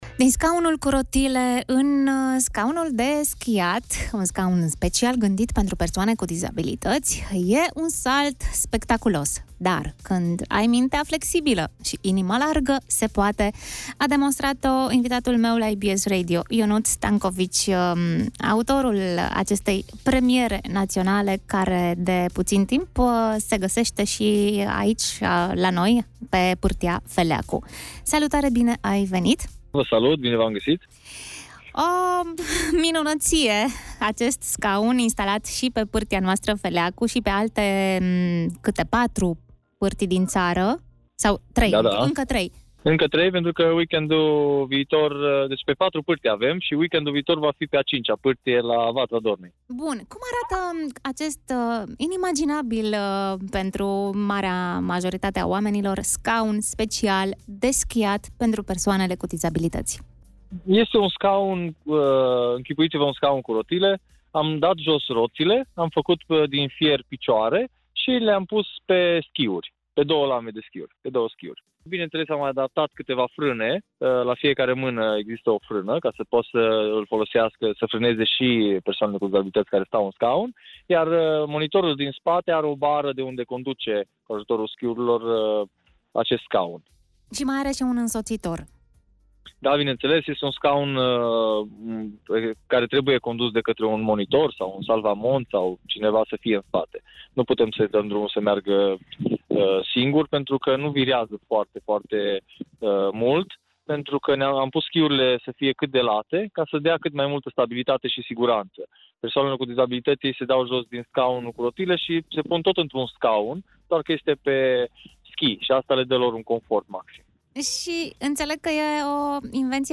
Întreaga poveste, în interviul